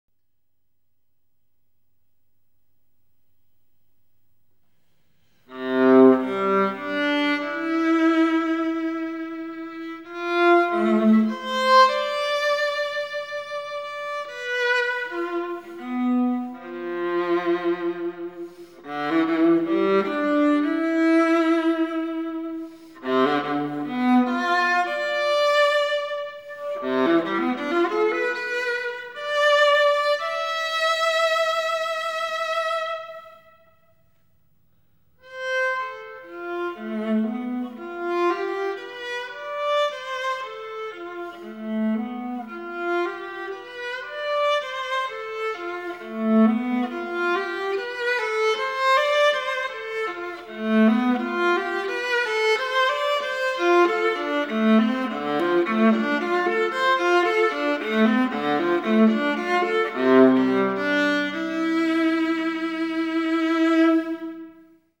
for Solo Vliola